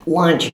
LAUNCH.wav